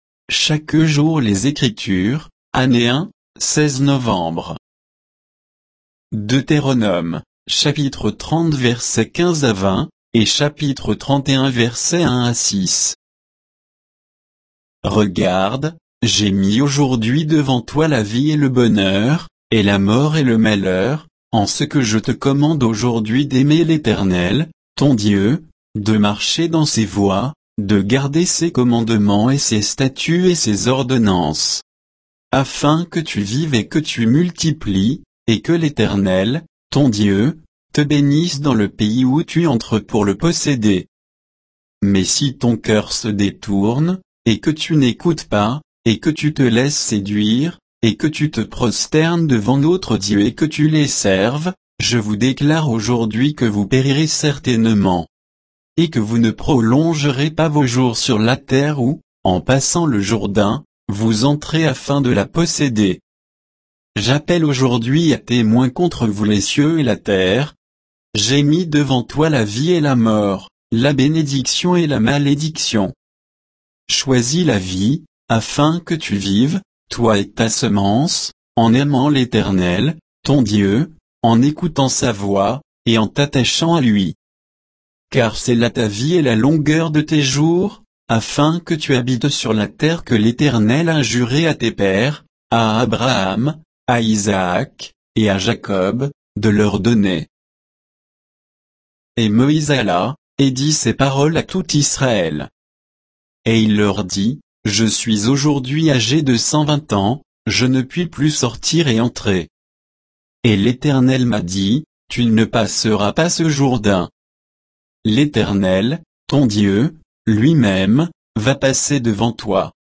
Méditation quoditienne de Chaque jour les Écritures sur Deutéronome 30, 15 à 31, 6